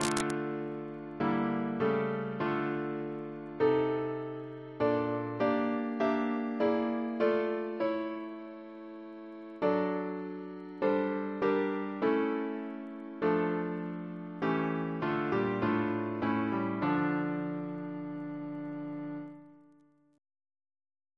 Double chant in D minor Composer: Herbert Hall Woodward (1847-1909) Reference psalters: ACP: 349